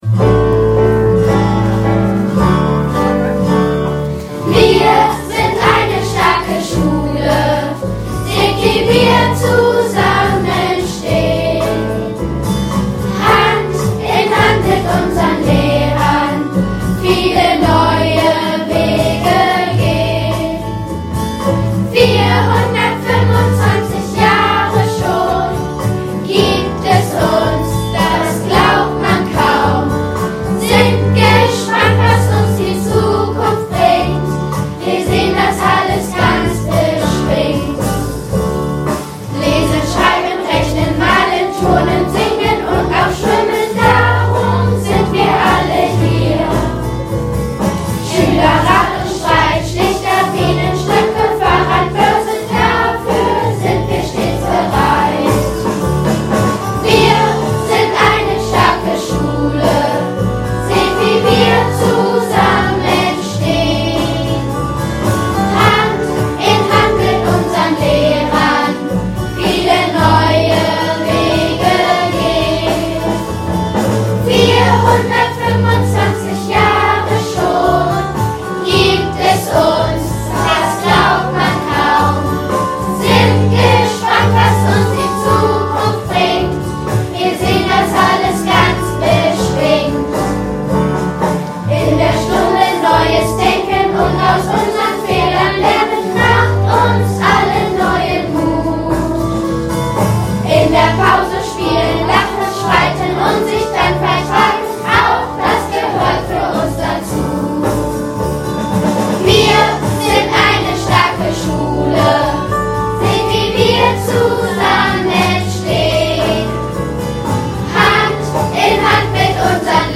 Schullied-mono.mp3